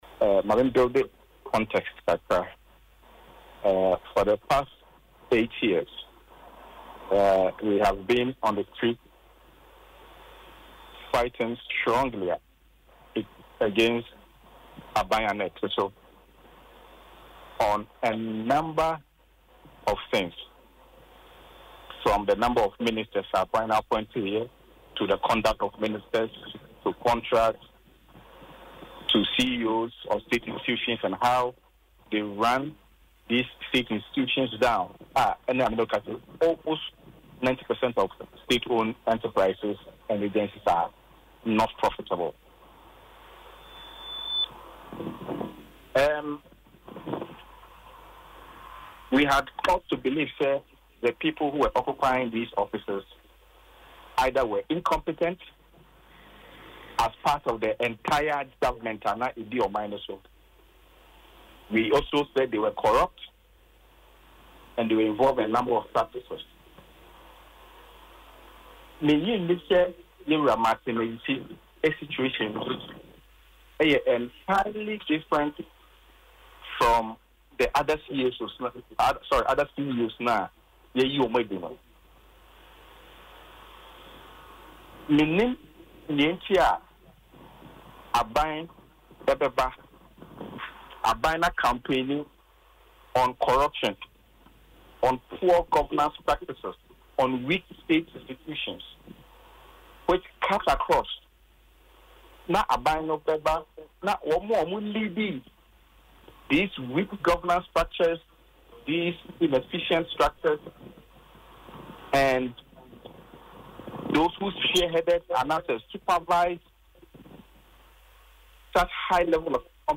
In an interview on Asempa FM’s Ekosii Sen